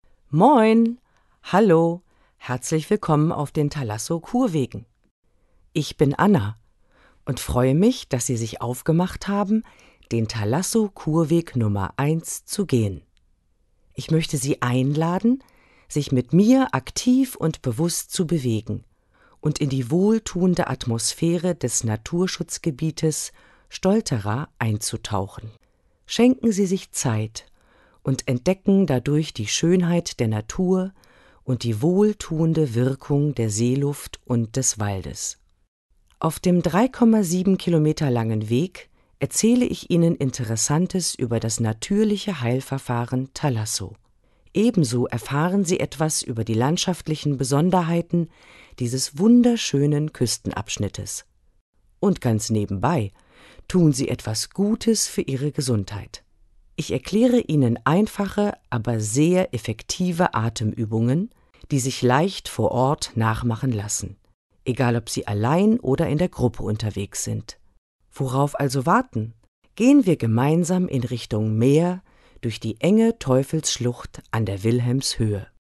Audioguide
Verantwortlich für die Tonaufnahmen: Tonstudio an der Hochschule Stralsund.